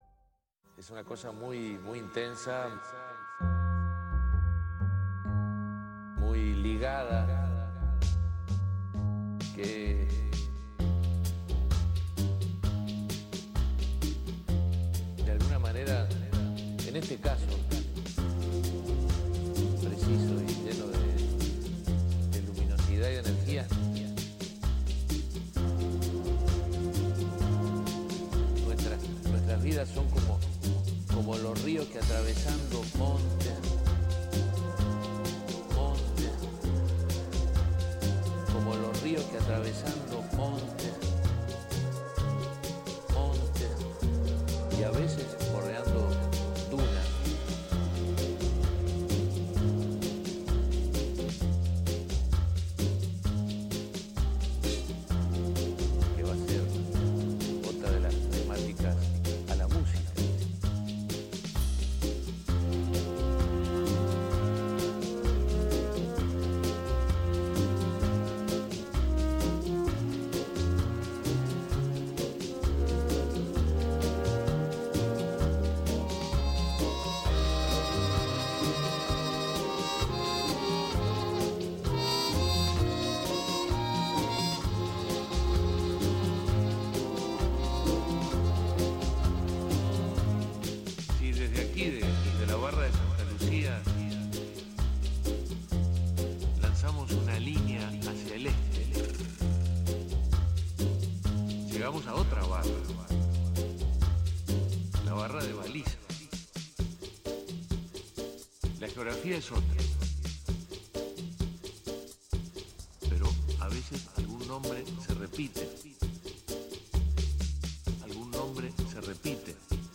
Η εκπομπή καταπιάνεται με γεωπολιτικά, γεωστρατηγικά, τεχνολογικά, ιατρικά και κοινωνικά ζητήματα φιλοξενώντας καθηγητές πανεπιστημίου, επιστήμονες, γιατρούς, ευρωβουλευτές και προέδρους πολιτικών ομάδων του ΕΚ καθώς και εκπροσώπους ΜΚΟ.